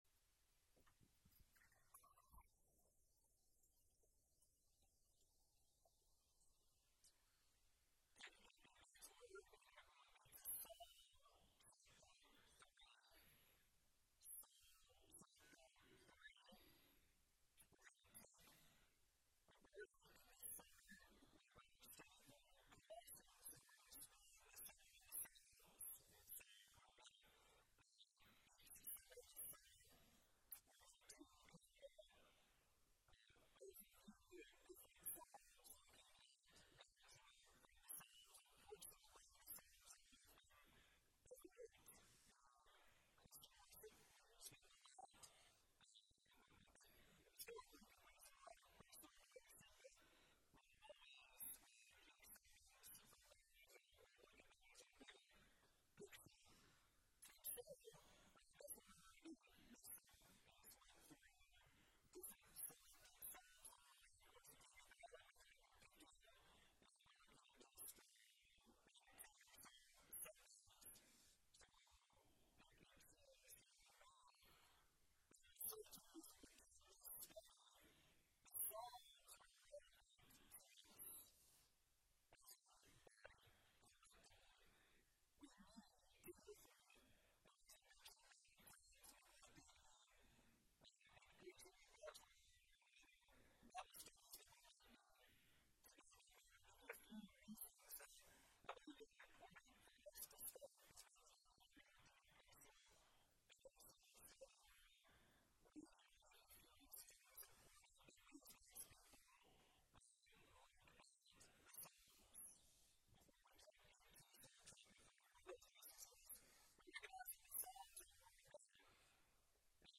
Sermon Series: Summer in Psalms